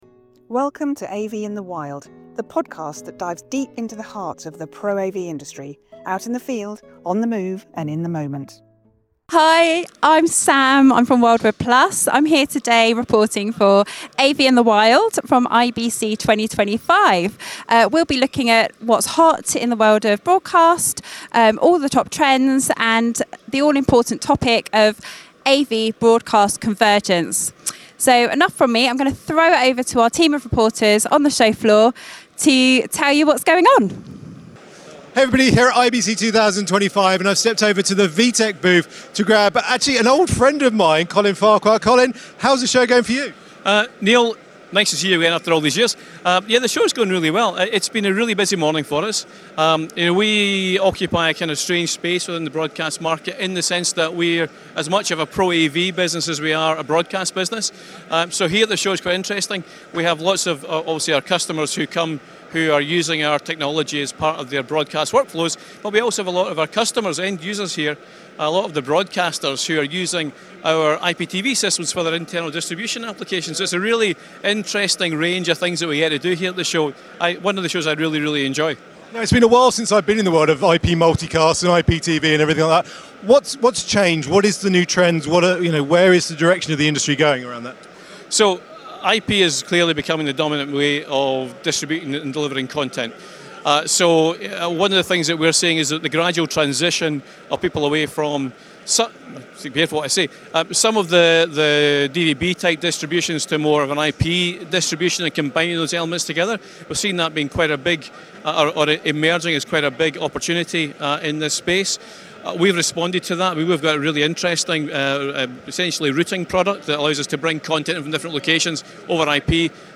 Location: IBC 2025, Amsterdam